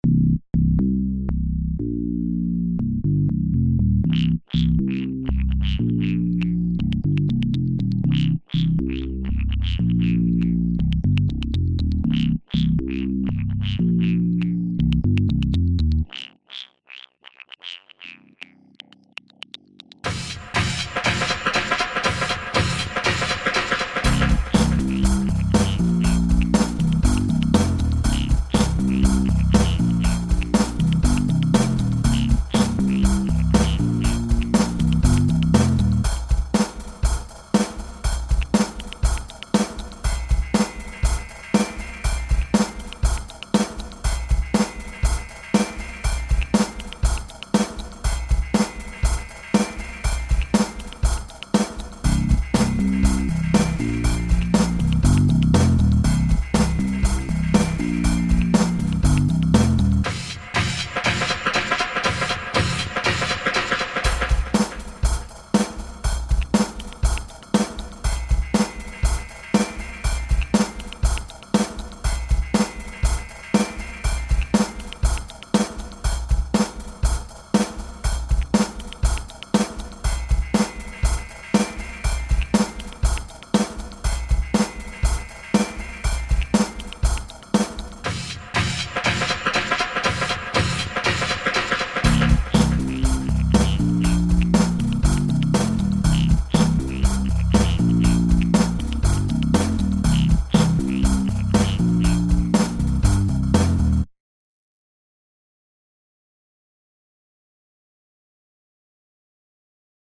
dance/electronic
Drum & bass
Ambient
Pop